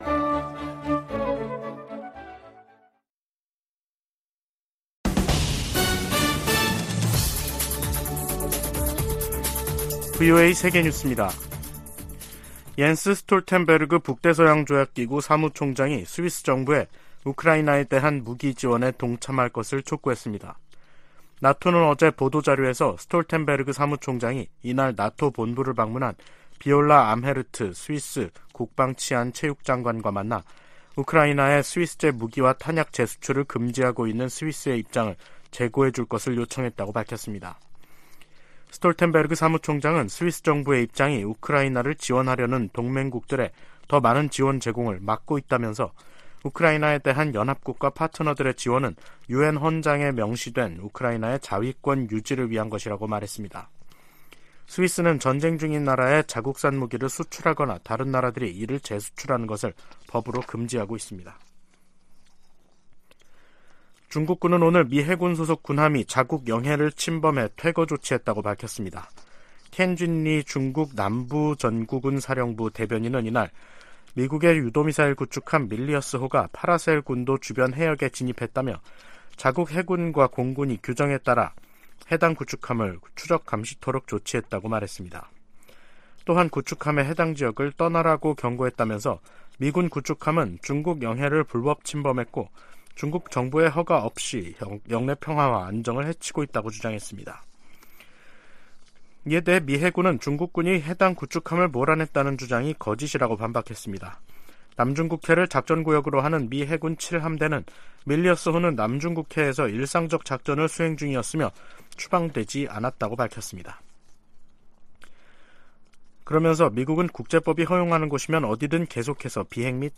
VOA 한국어 간판 뉴스 프로그램 '뉴스 투데이', 2023년 3월 23일 2부 방송입니다. 백악관은 북한의 핵 공격이 임박했다는 징후는 없지만 최대한 면밀히 주시하고 있다고 밝혔습니다. 북한은 국제사회의 비핵화 요구를 핵 포기 강요라며 선전포고로 간주하고 핵으로 맞서겠다고 위협했습니다. 미국 국무부가 한국 정부의 최근 독자 대북제재 조치에 환영의 입장을 밝혔습니다.